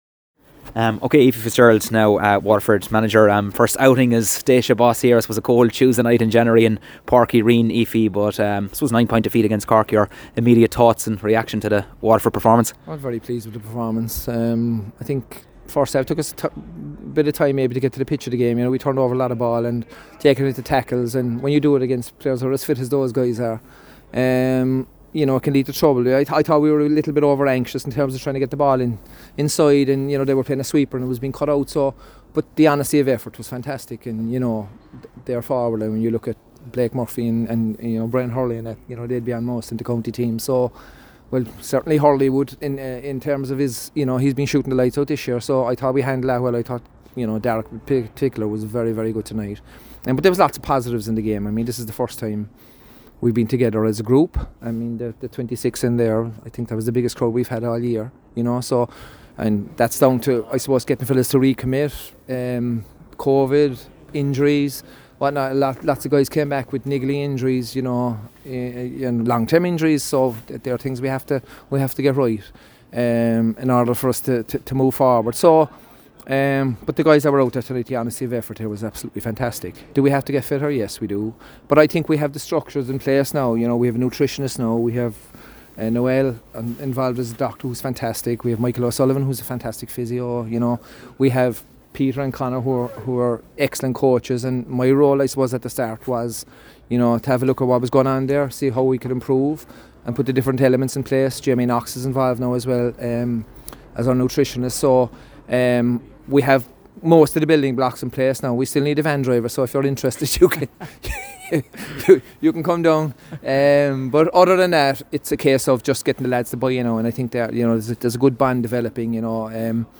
post-match conversation